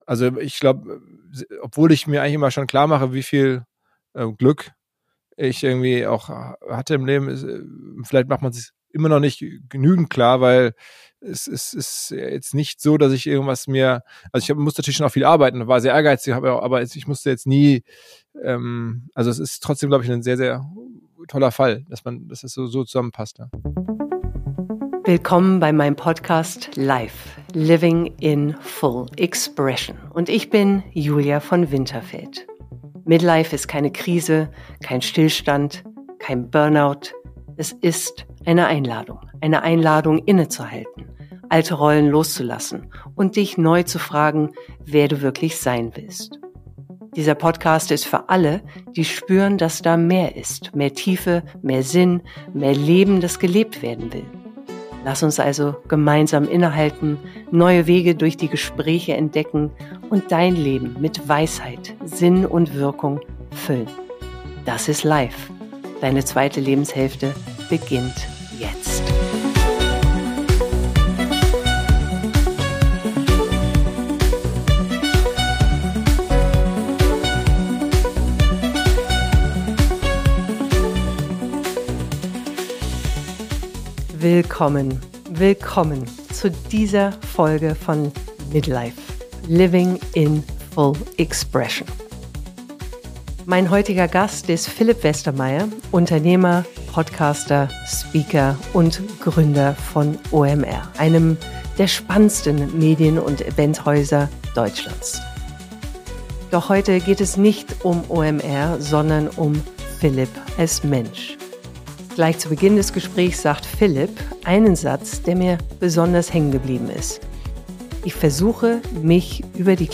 Er erzählt von Schlüsselmomenten auf seinem Weg, von wirtschaftlichem Ehrgeiz, innerer Zufriedenheit und der Kunst, sich am Alltag zu freuen. Wir sprechen über Krisen, Wendepunkte und die Frage, was die zweite Lebenshälfte mit uns macht – oder machen darf. Ein ehrliches, bodenständiges und inspirierendes Gespräch über das Leben mitten im Leben.